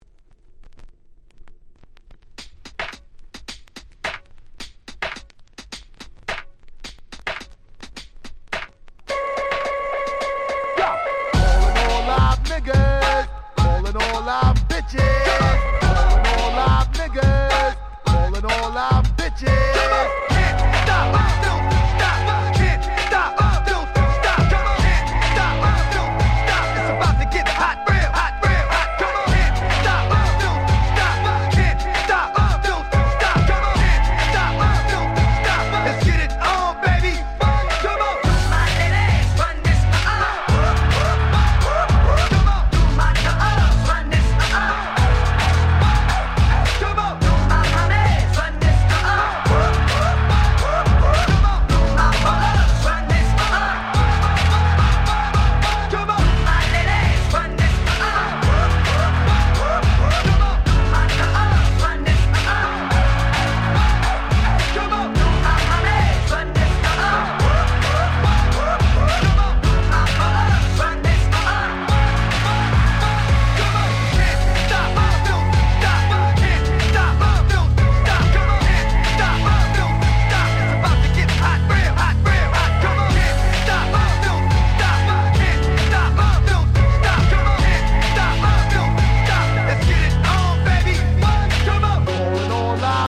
Main Streamヒットを更にフロア仕様にこの盤オンリーとなるRemixを施した使えるシリーズ！！
全6曲全部アゲアゲ！！
勝手にRemix 勝手にリミックス Hip Hop R&B 00's